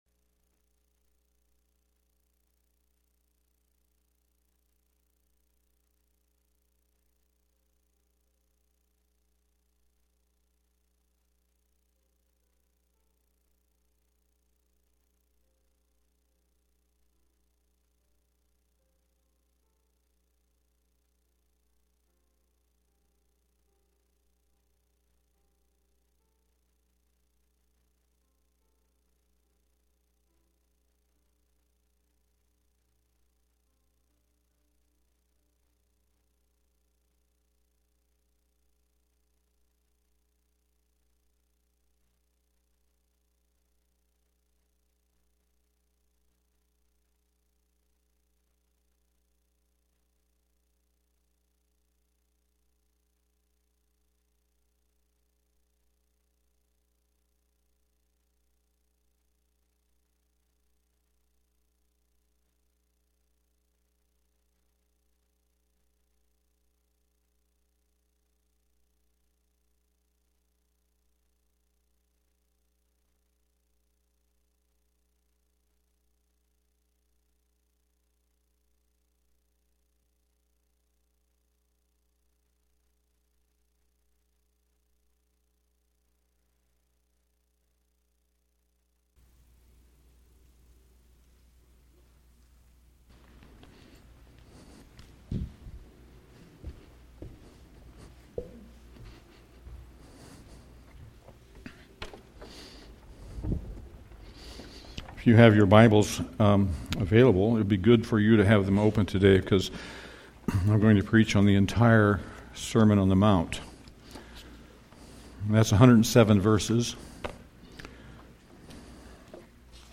Passage: Matthew 5:13-20 Service Type: Sunday AM Bible Text